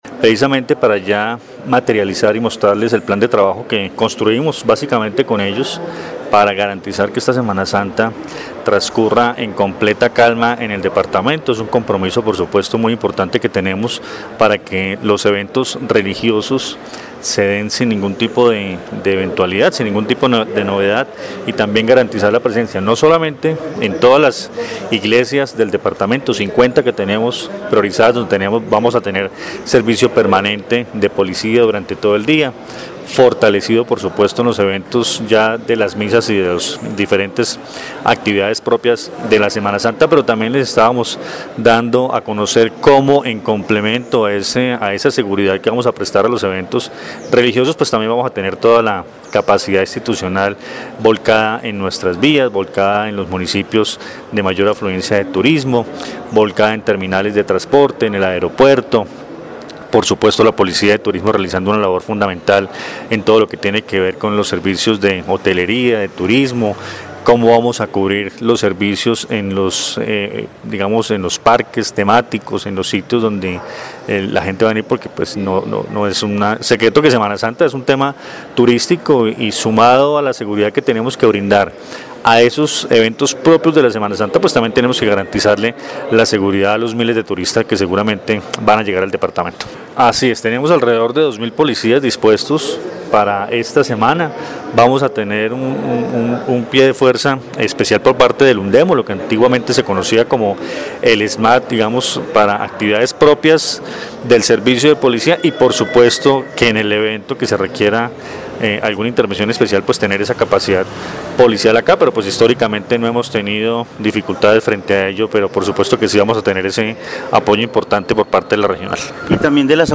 Coronel Luis Fernando Atuesta, Comandante Policía, Quindío
En Caracol Radio Armenia hablamos con el Comandante de la Policía del Quindío, Coronel Luis Fernando Atuesta y toda la estrategia de seguridad en los 12 municipios del departamento en esta semana santa 2025.